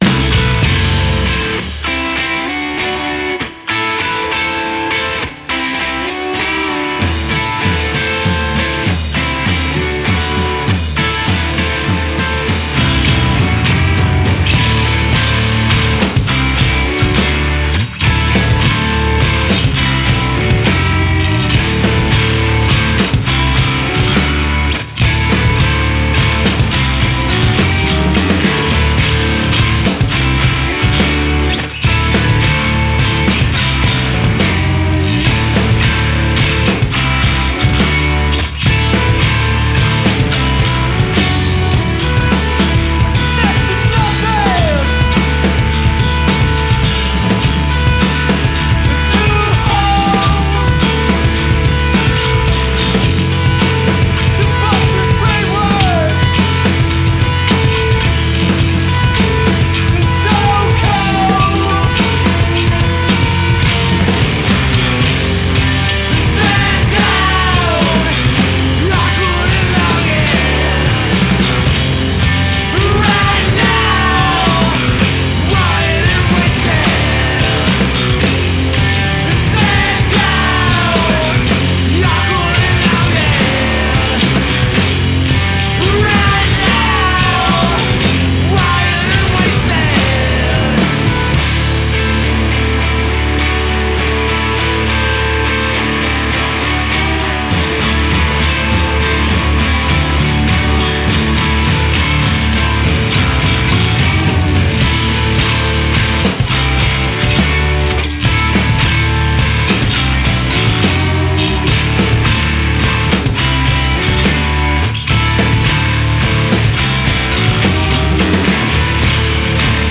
Bass
Drums
Guitar / Vocals
Emo , Indie , Rock